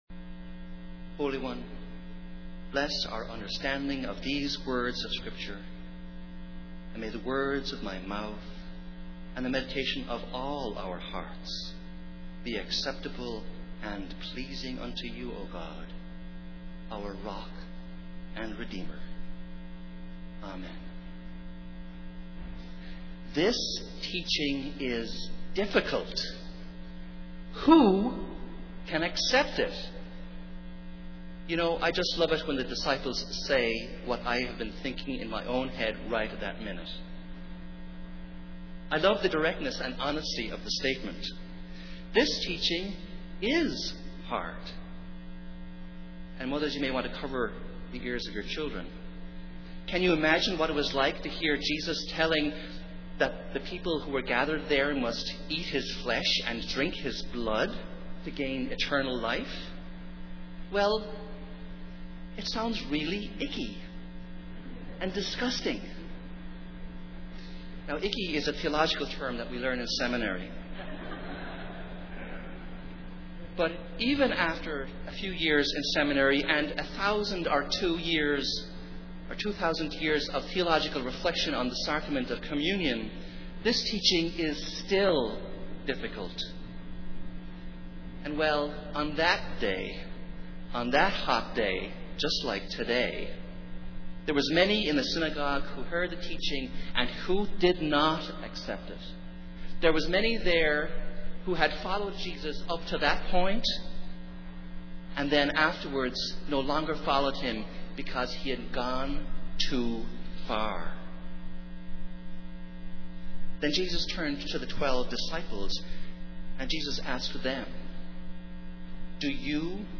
Festival Worship
Joshua 24:1-2a, 14-18 (responsively)